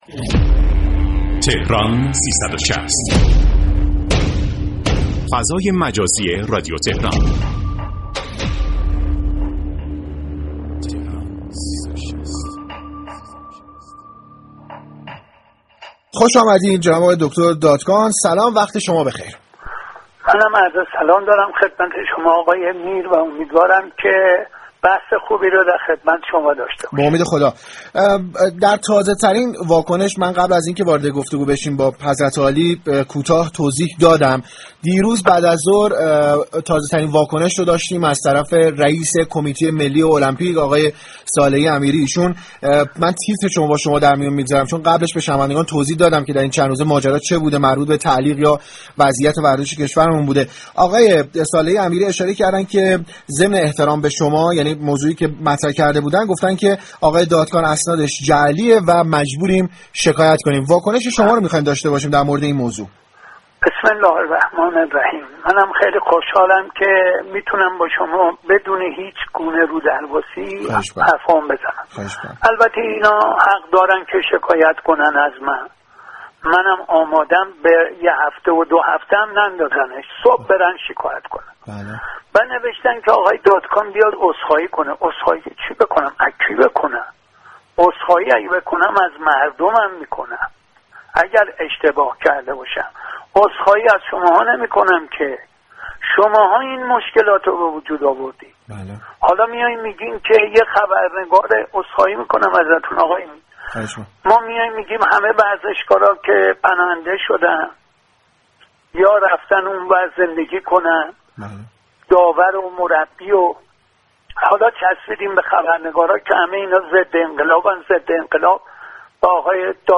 تهران ورزشی در راستای شفاف‌سازی واقعیت‌های فوتبال كشور و حواشی كه این روزها با آشكار شدن مفاد قراردادی كه فدراسیون فوتبال با مارك ویلموتس، مربی بلژیكی بسته و مشكلات ایجاد شده در كمیته‌ی ملی المپیك با مهندس محمد دادكان گفتگو كرد.